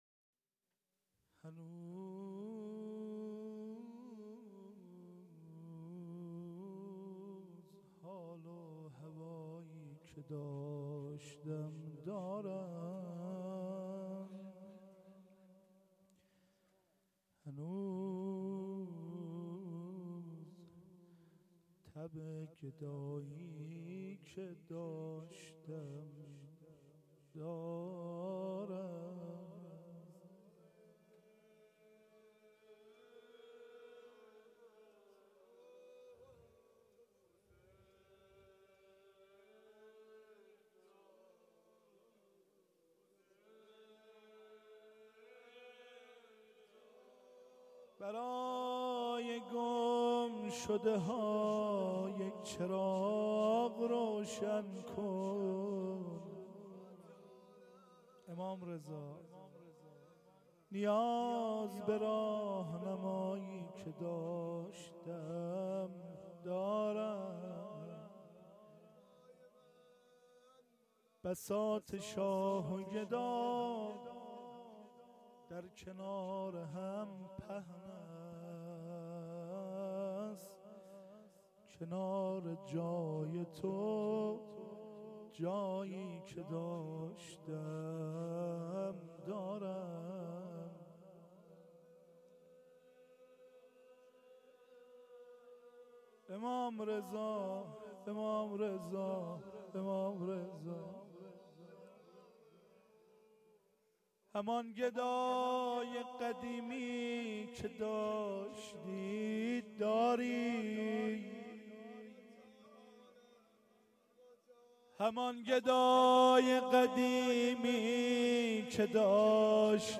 روضه آخر